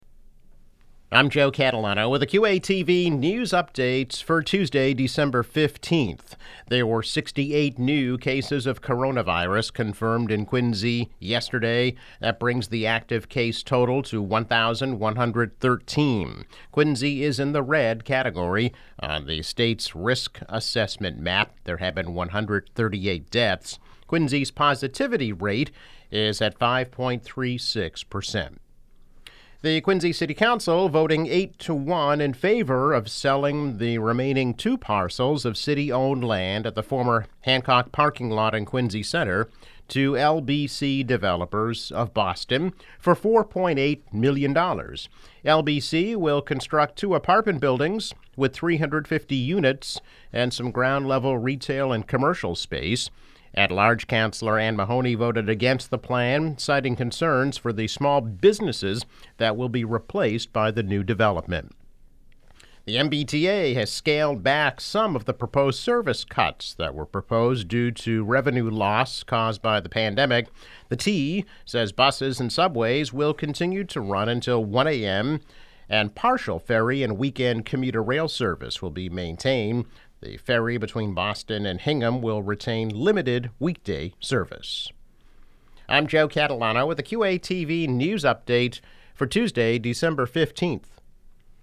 News Update - December 15, 2020